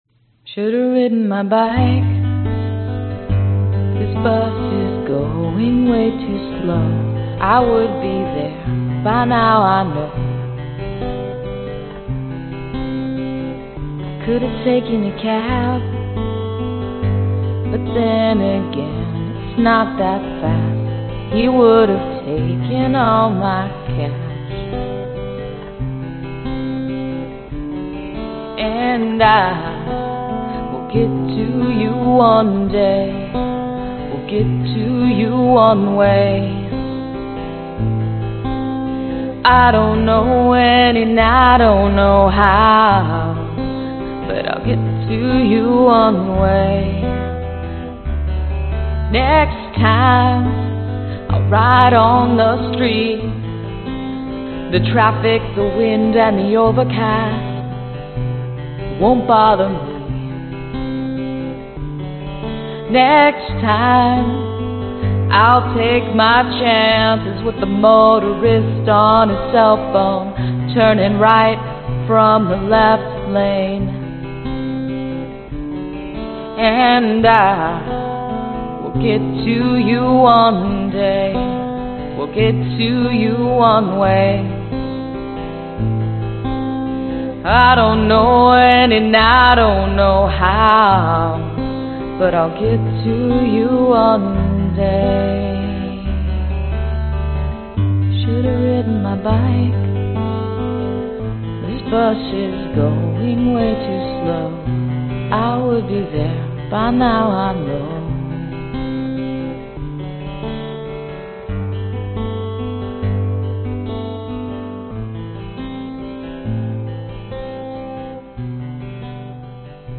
原声吉他用Walden钢弦录制。
DADGAD。
原声 吉他 女声 寒意 低速 民谣